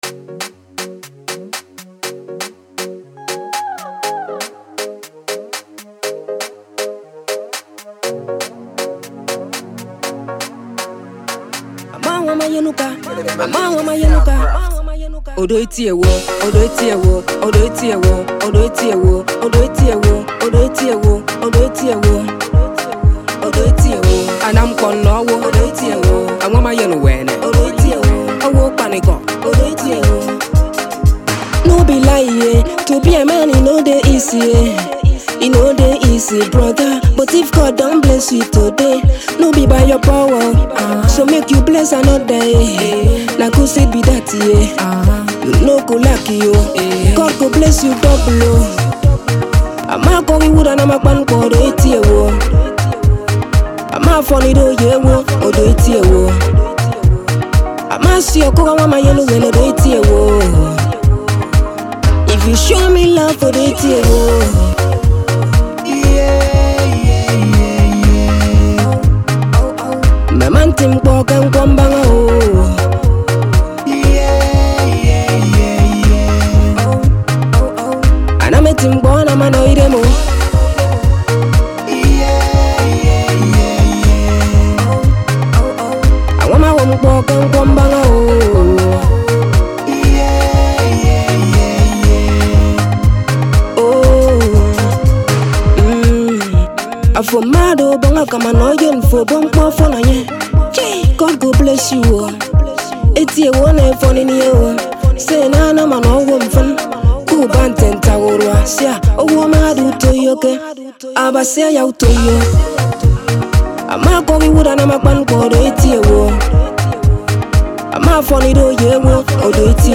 pop
wavy tune